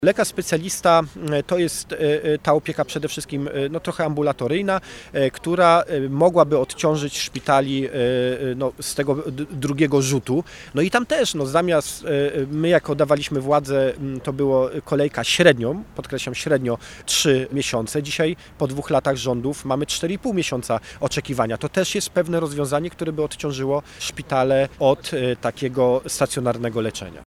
Wiceprezes PiS Elżbieta Witek, a także posłowie Paweł Hreniak, Jacek Świat oraz radni Sejmiku Województwa Dolnośląskiego spotkali się przy szpitalu uniwersyteckim we Wrocławiu, by zaapelować do rządu o podjęcie działań ws. ochrony i służby zdrowia.
Poseł Paweł Hreniak dodaje, iż czas oczekiwania na wizytę u lekarza specjalisty jest stanowczo za długi.